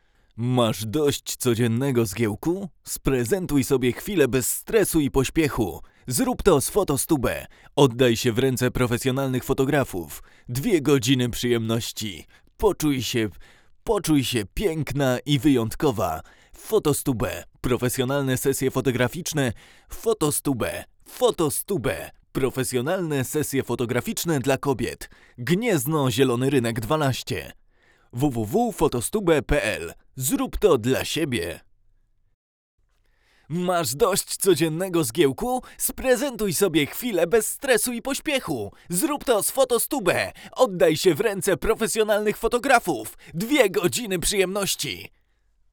Zwraca uwagę niski poziom szumów własnych, niewątpliwy plus.
Dźwięk jest mocno obcięty w niskim paśmie, podbity w średnim i ogólnie w charakterze przypomina trochę tańsze produkcje tranzystorowe.
Na dodatek, na wszystkich głosach wyszła ponadprzeciętna wrażliwość konstrukcji na głoski wybuchowe.